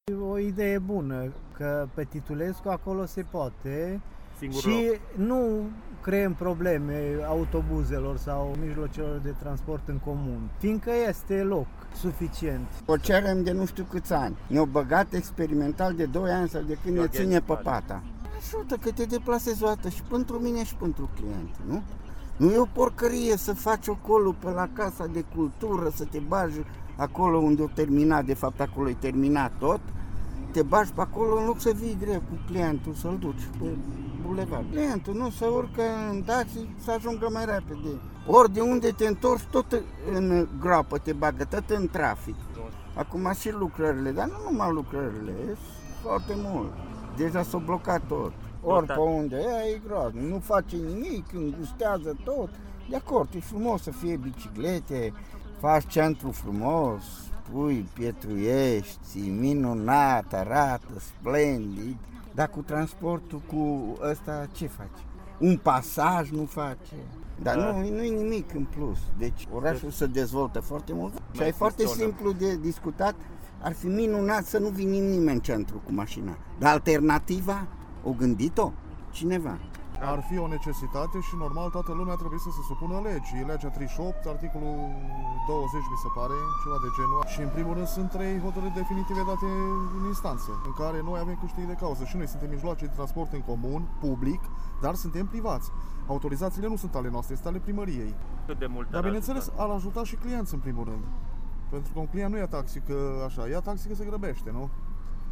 Ce ne-au declarat mai mulți taximetriști clujeni pe marginea acestui subiect, mai jos: